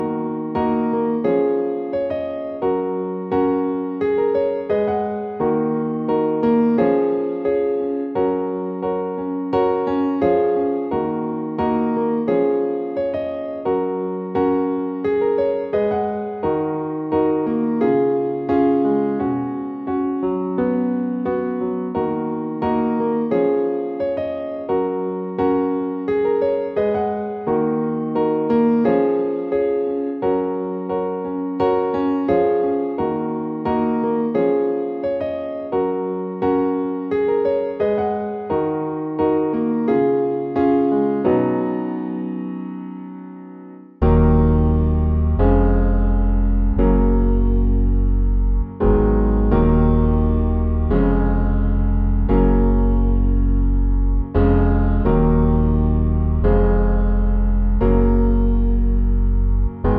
Piano on Verse 1 Pop (2010s) 3:29 Buy £1.50